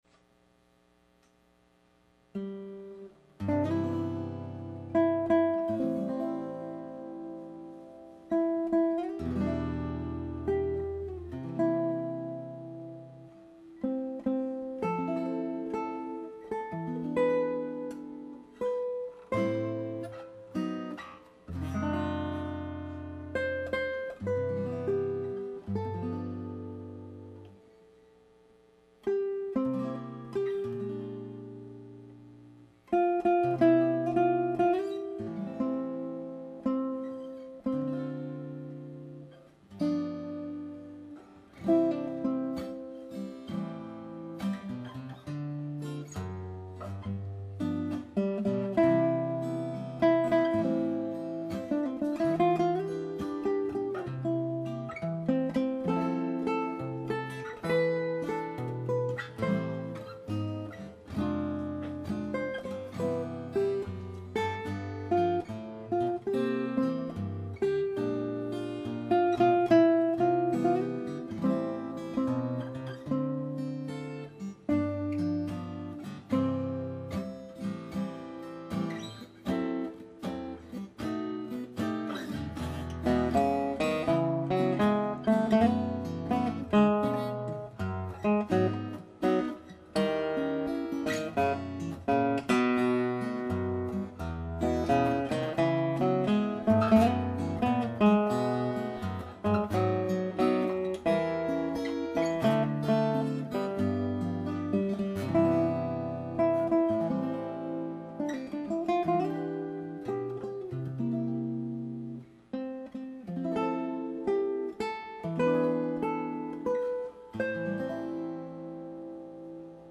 guitar
This husband and wife guitar duo have delighted audiences for over a decade gracing many fine occasions with their sophisticated stylings and extensive repertoire.